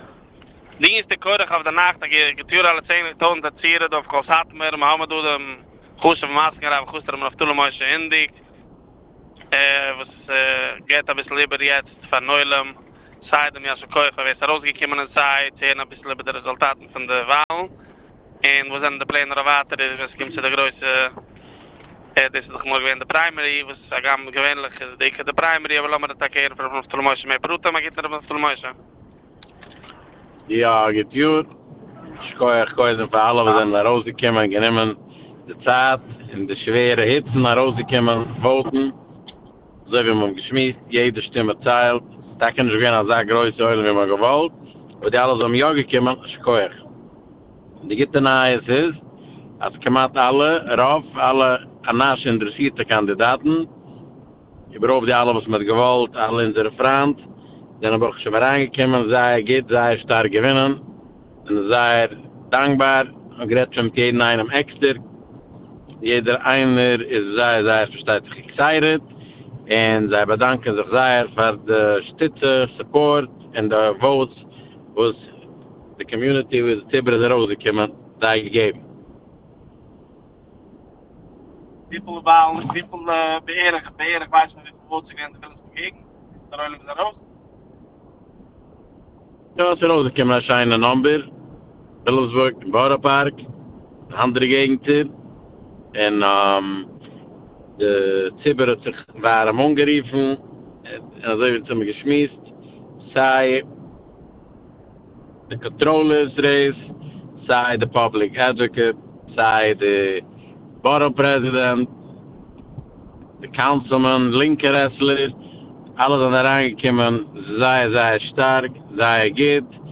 אינטערוויא